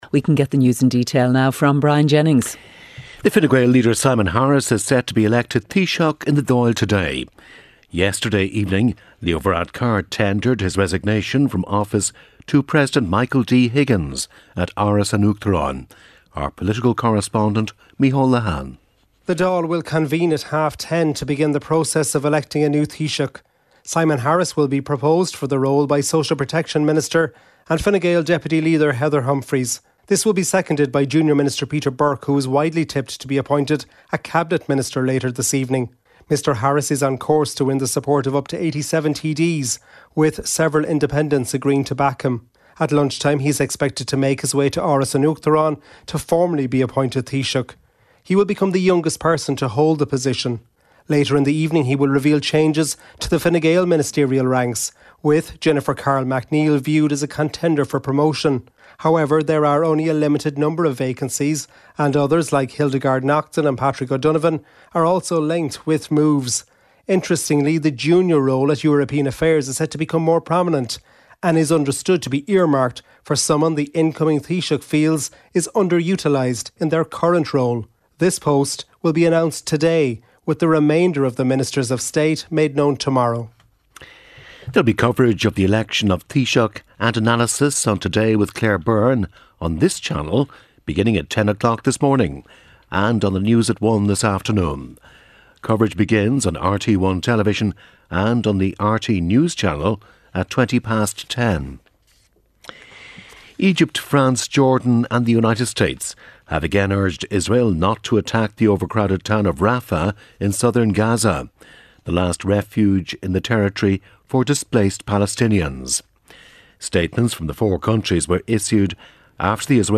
8am News Bulletin - 09.04.2024